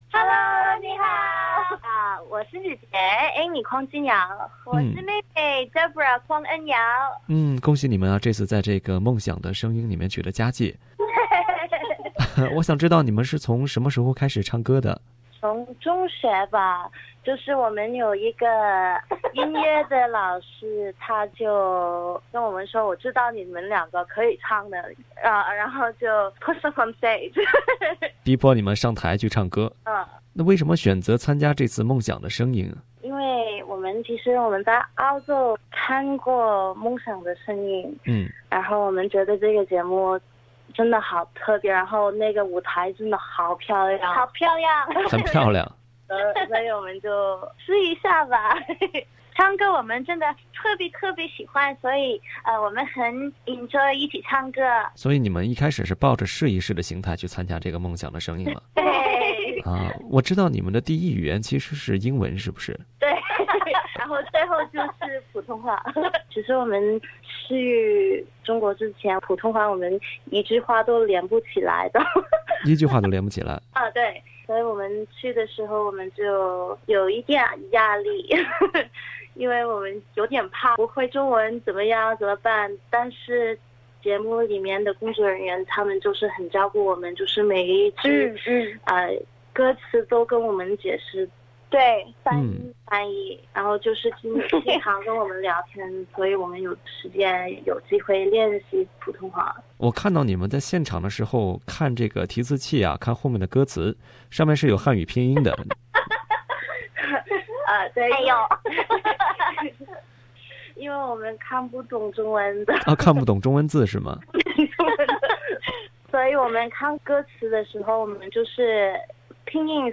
姐妹俩在采访时讲述了自己的参赛经历